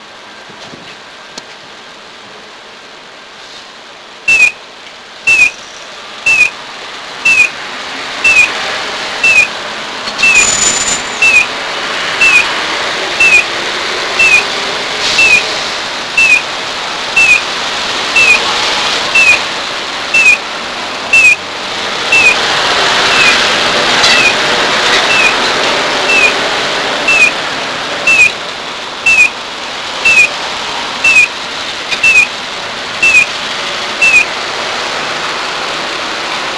また、未編集なために音が悪かったりしますがそちらもご了承ください。
3、4ファイル目は岡山県で見つけた京三製警交仕規第21号です。
昭和54年3月製造とだいぶ古いためか音質がほかのと少し違うように思えます。